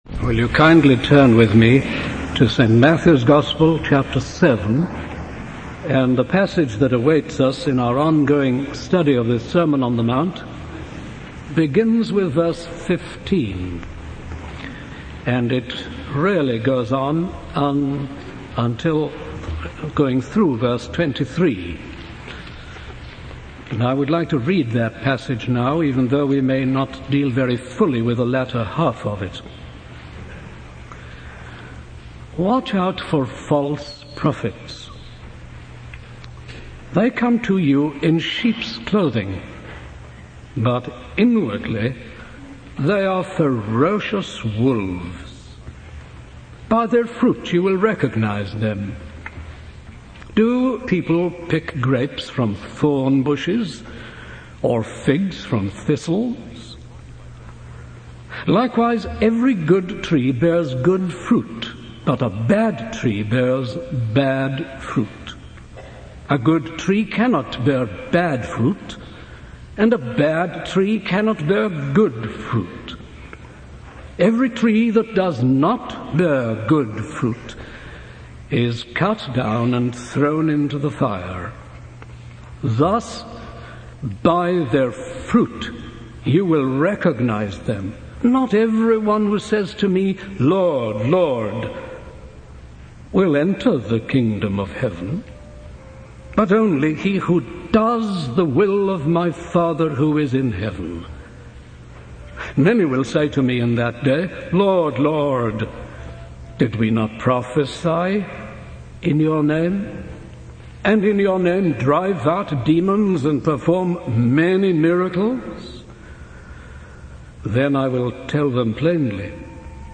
In this sermon, the speaker emphasizes the importance of discerning false prophets and finding the narrow gate that leads to life. He cautions against adopting a judgmental attitude towards others, as we are not infallible judges. The key to finding the narrow gate is to listen to and practice the words of Jesus, building our lives on the rock of God's Word.